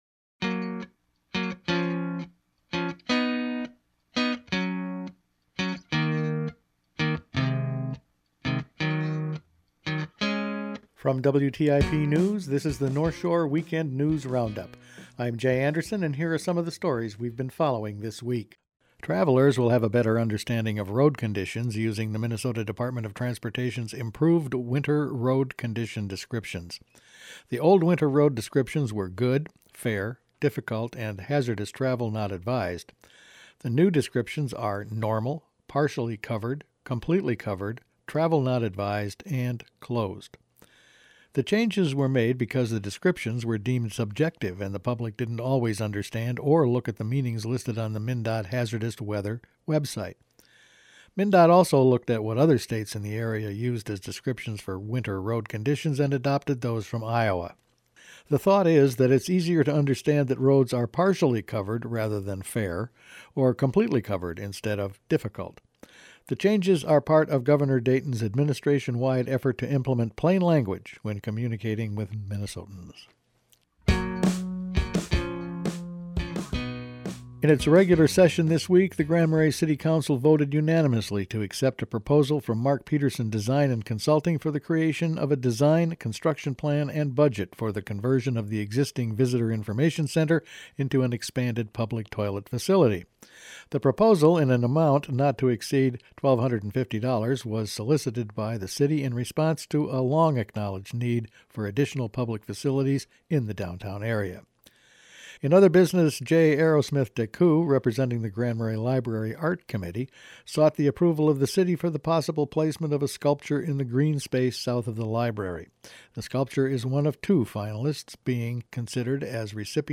Weekend News Roundup for October 11
Each week the WTIP news department puts together a roundup of the weeks top news stories. New MnDOT road condition definitions, City Council decisions, and PolyMet EIS information…all figured in this week’s news.